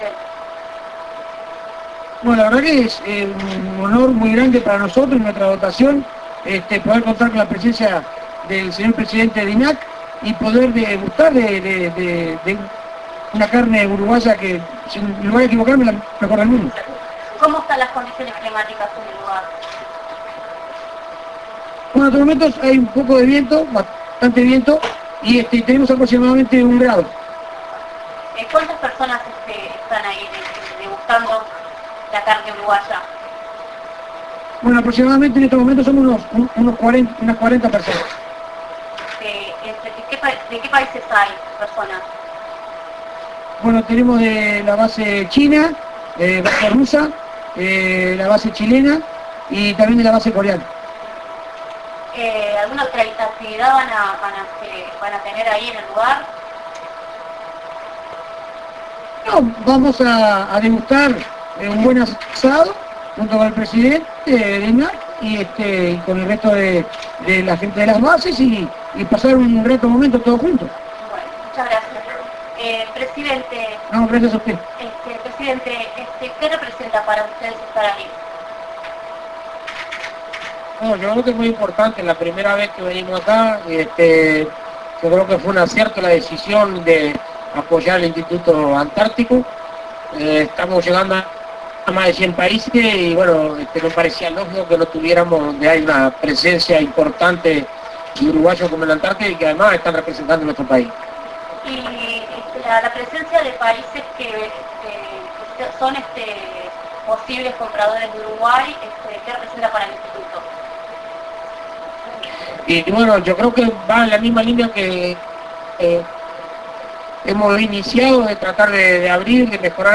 Teleconferencia